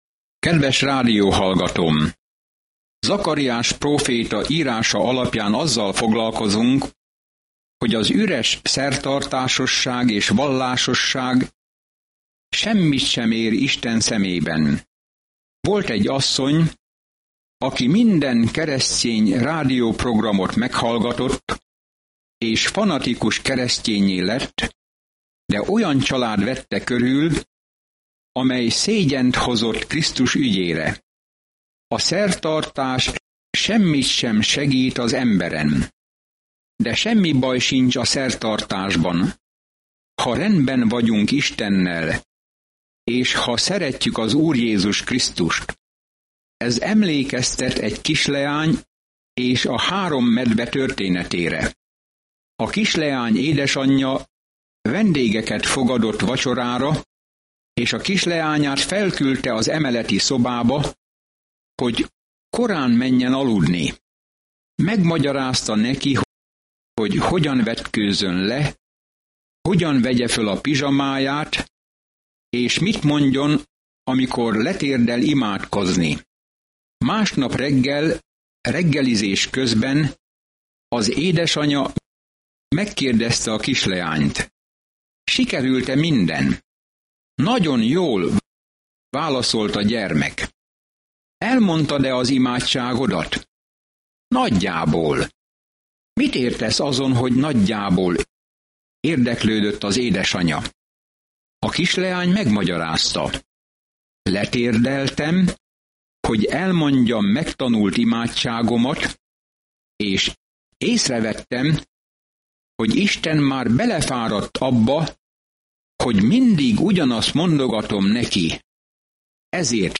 Szentírás Zakariás 7:8-10 Nap 13 Olvasóterv elkezdése Nap 15 A tervről Zakariás próféta látomásokat oszt meg Isten ígéreteiről, hogy reményt adjon az embereknek a jövőre nézve, és arra buzdítja őket, hogy térjenek vissza Istenhez. Napi utazás Zakariáson keresztül, miközben hallgatod a hangos tanulmányt, és olvasol válogatott verseket Isten szavából.